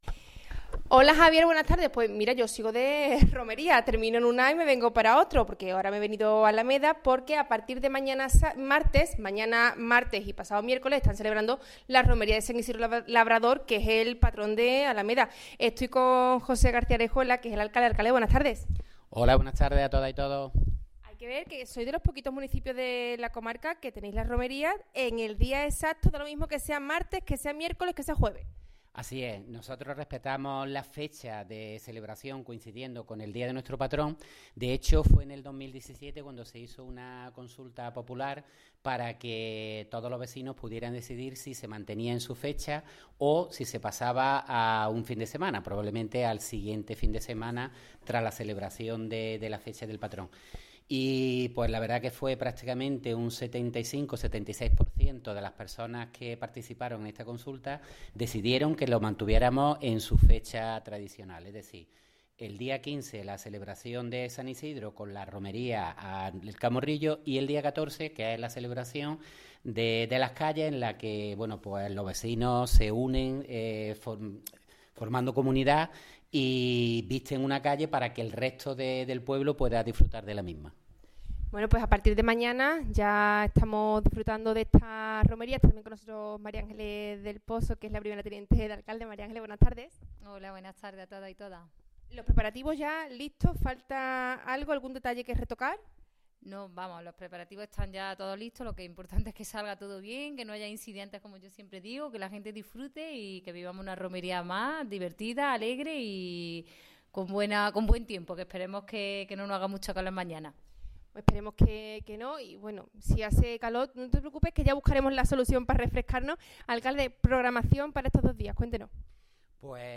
Directo Romería Alameda 2024.